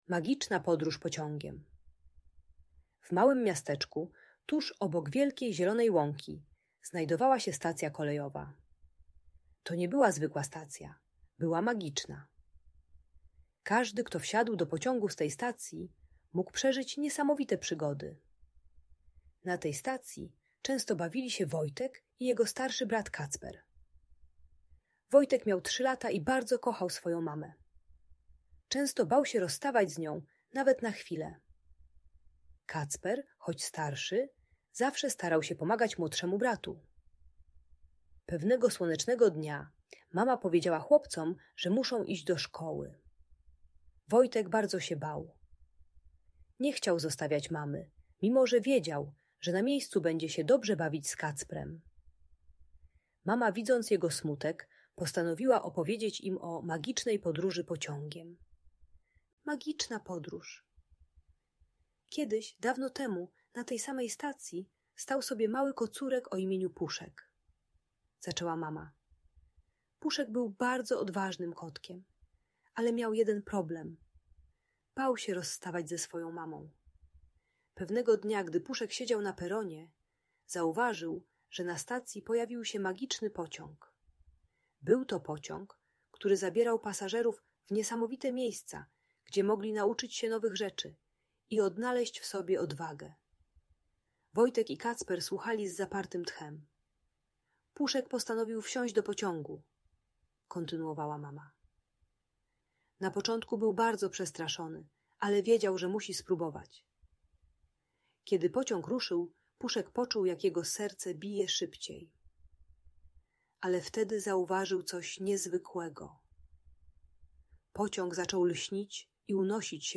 Magiczna podróż pociągiem - Przywiązanie do matki | Audiobajka